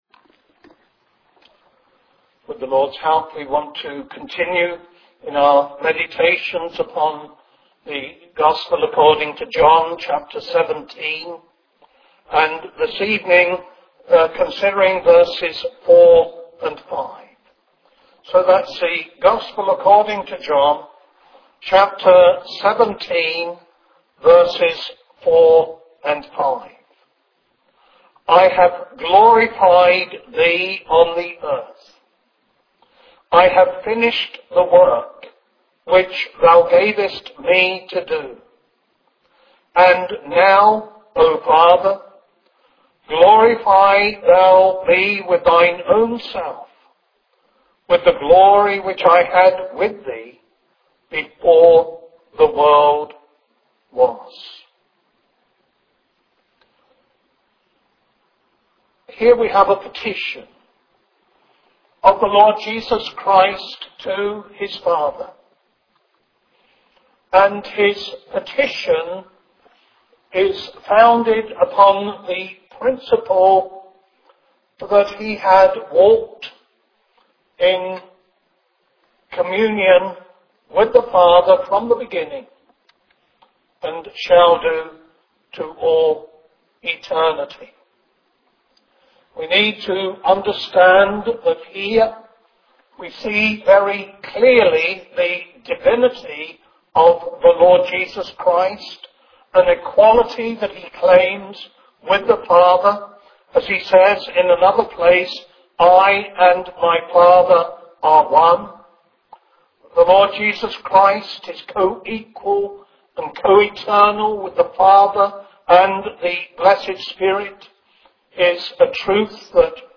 This is an audio sermon